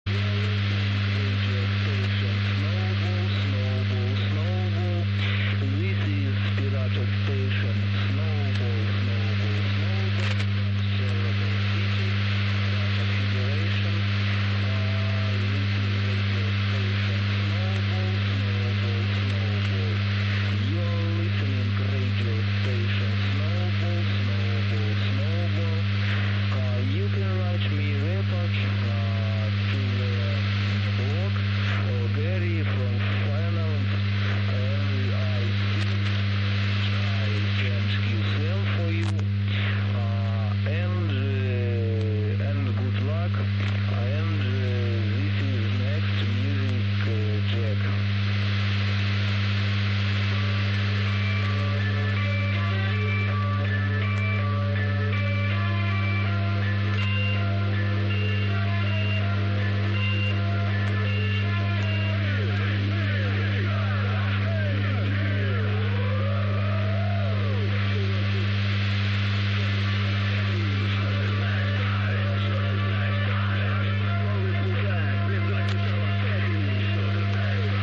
Hier ein paar audio files des russ. Piraten RADIO SNOWBALL. Die Aufahmen wurden in verschiedenen Teilen Europas gemacht, was natürlich auch die unterschiedliche Qualität der einzelnen Mitschnitte erklärt.